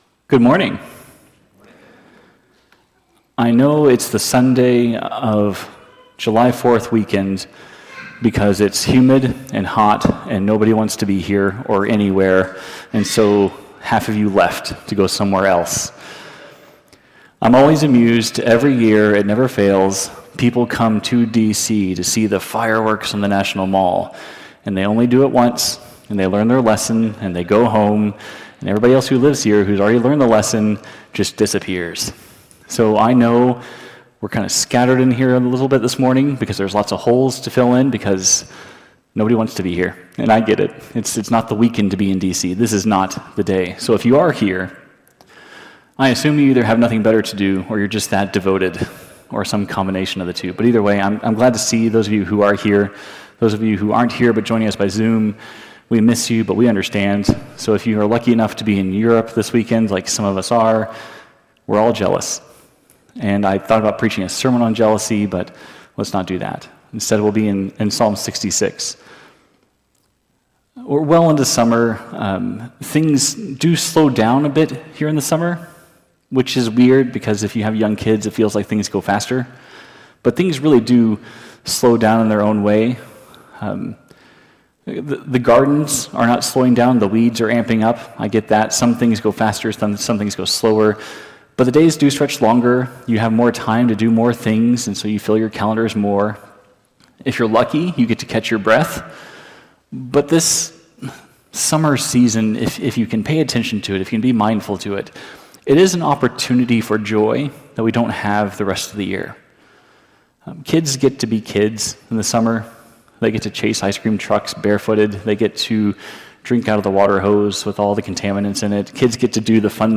The sermon encourages living as grateful witnesses to God’s faithfulness in all circumstances.